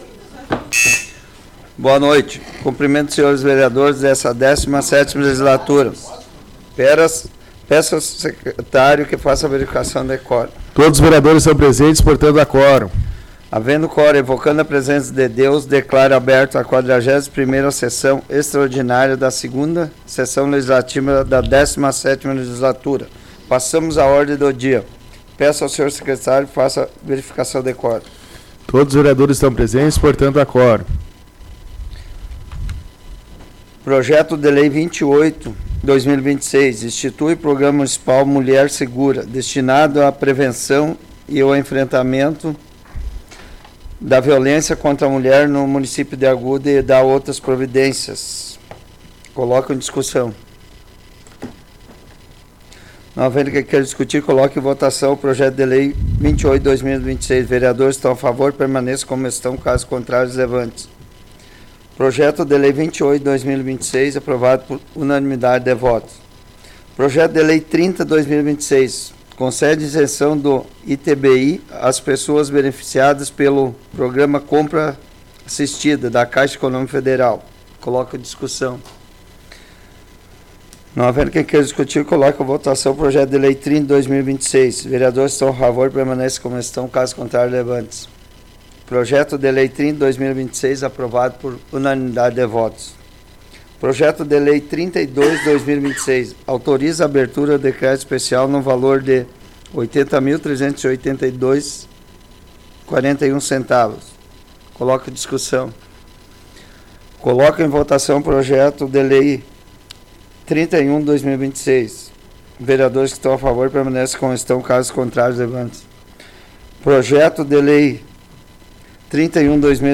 Áudio da 41ª Sessão Plenária Extraordinária da 17ª Legislatura, de 06 de abril de 2026